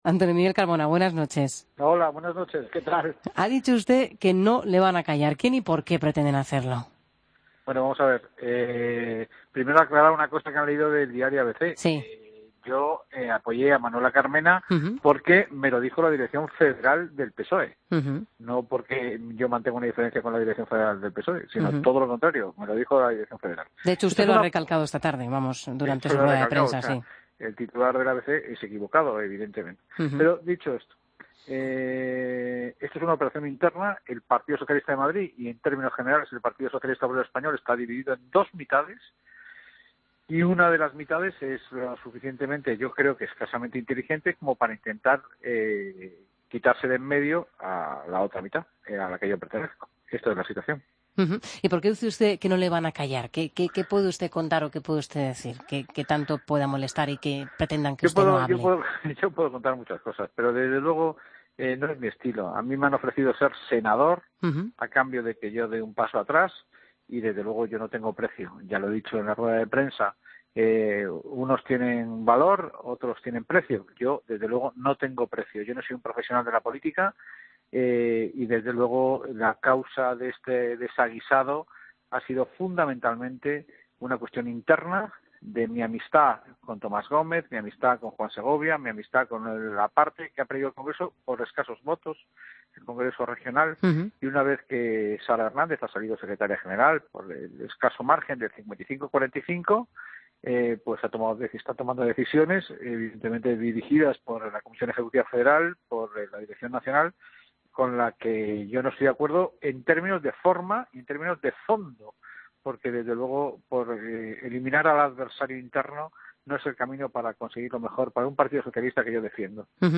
Escucha la entrevista al socialista Antonio Miguel Carmona en La Linterna